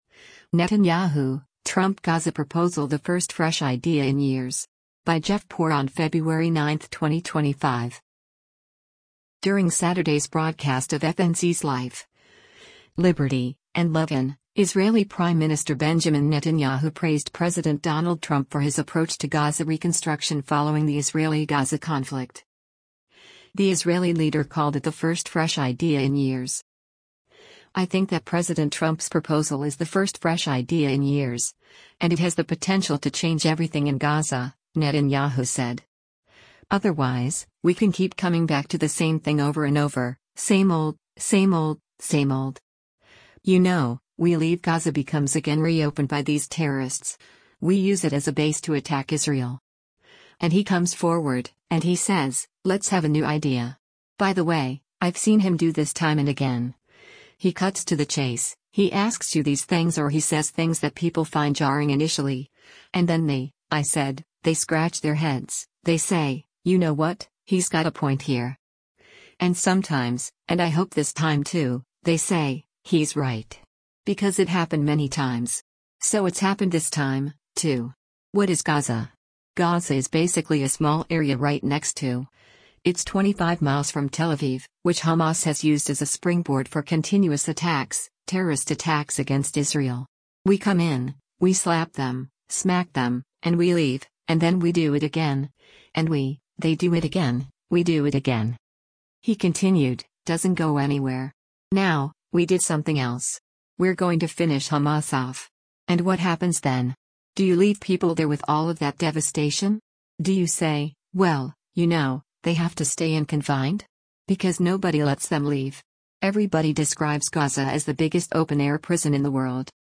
During Saturday’s broadcast of FNC’s “Life, Liberty & Levin,” Israeli Prime Minister Benjamin Netanyahu praised President Donald Trump for his approach to Gaza reconstruction following the Israeli-Gaza conflict.